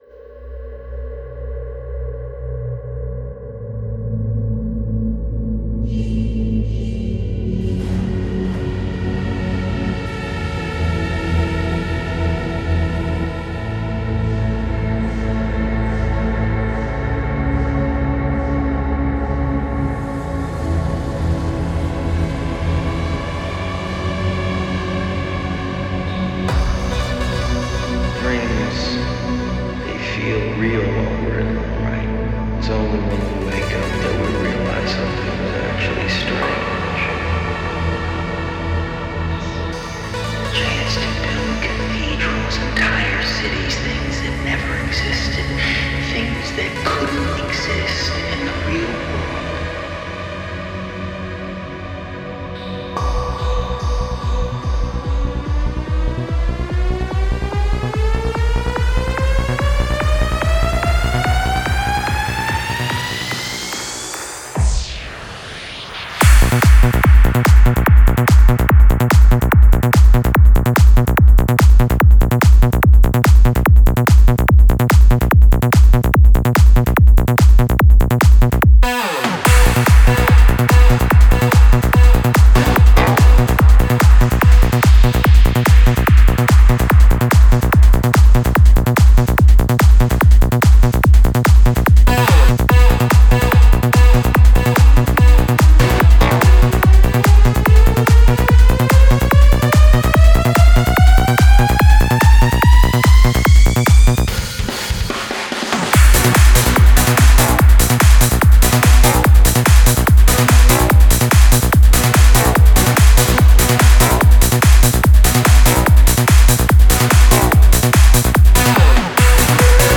Style: FullOn
Quality: avg. 252kbps / Joint Stereo